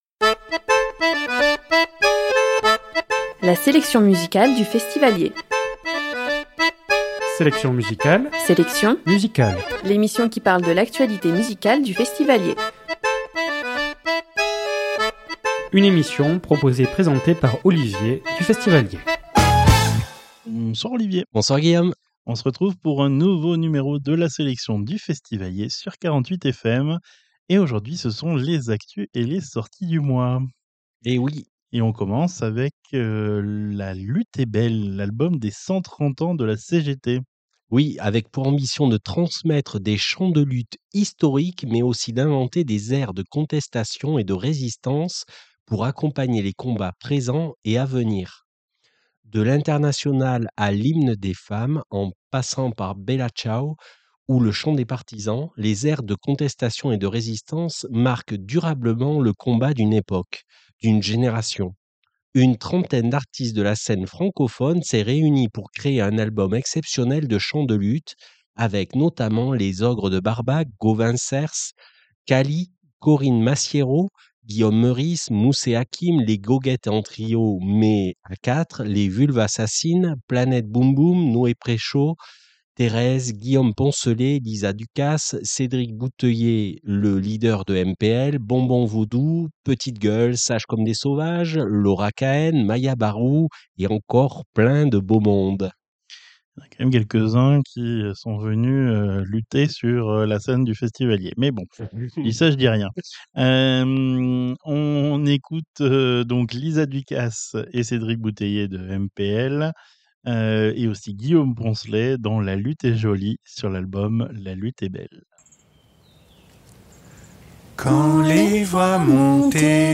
Emission du vendredi 14 novembre 2025 à 19hRediffusion le dimanche suivant à 21h